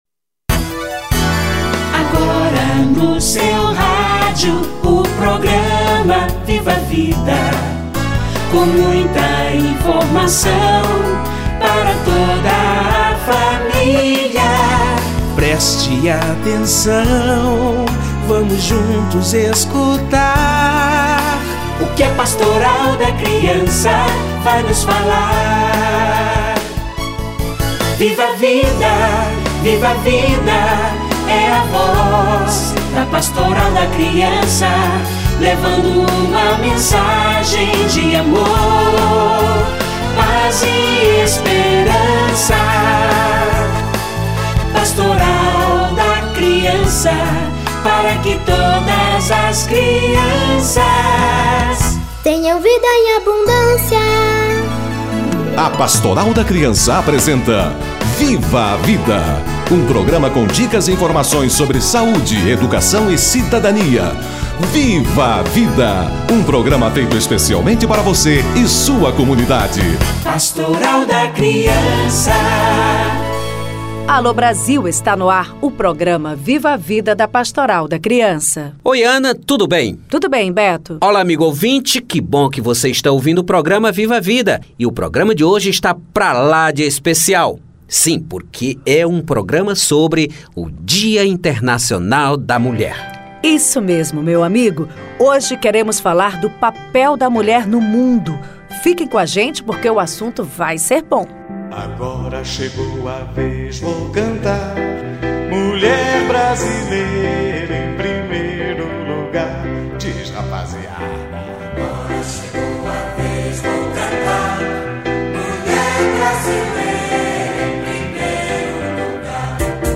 Dia Internacional da Mulher - Entrevista